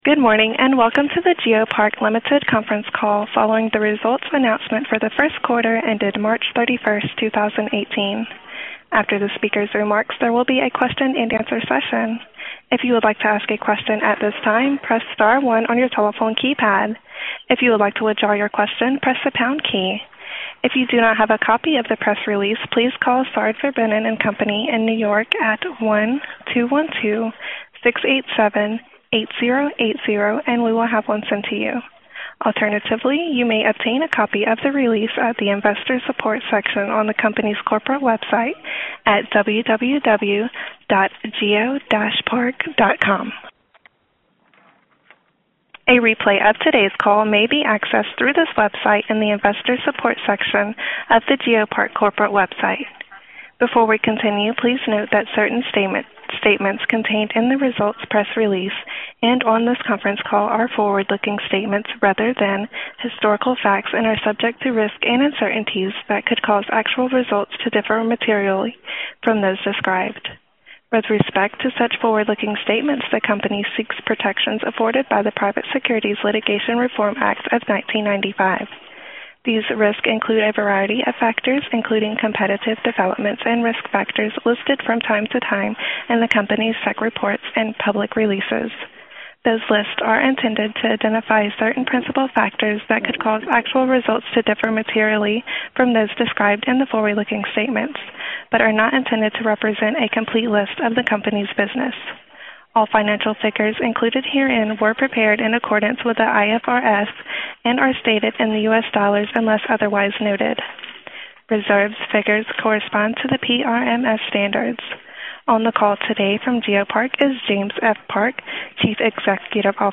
geopark-1q18-earnings-call-audio-recording.mp3